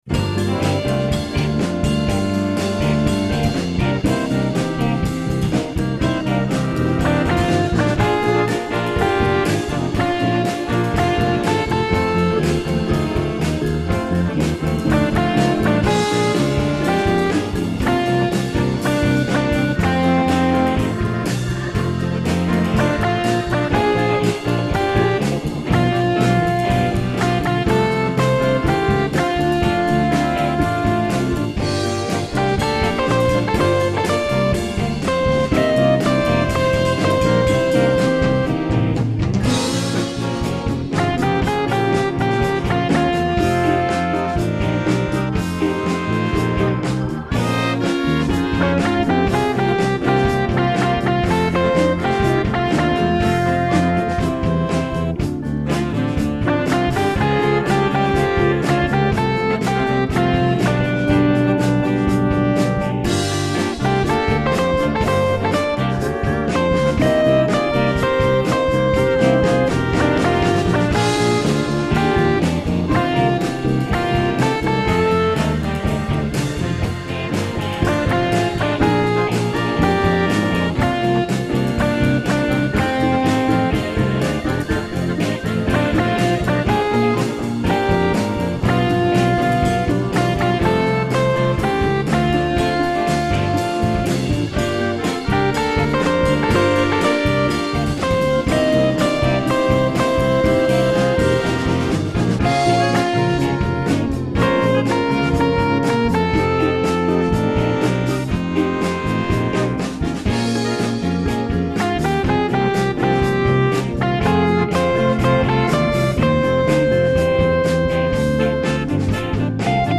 My backing is a messy blues.